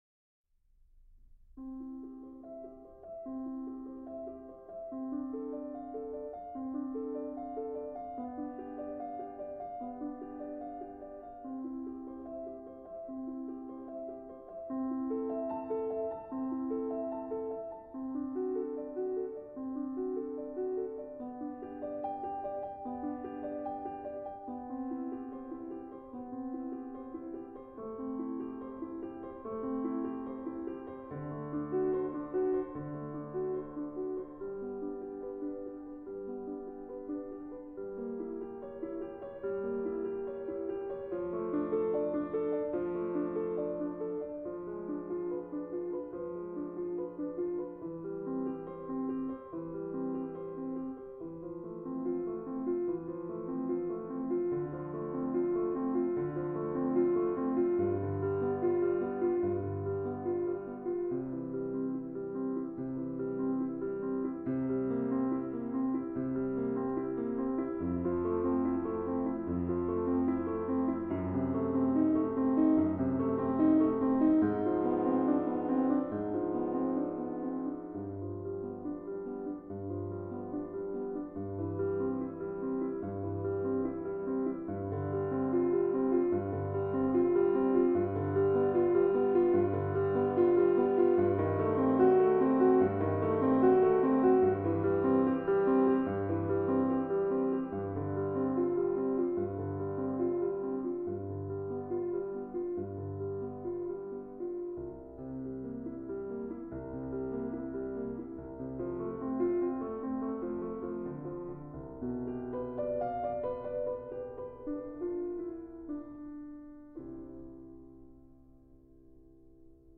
پرلود ساده و مینیمال به نظر میرسد اما ساختاری دقیق و هوشمندانه دارد.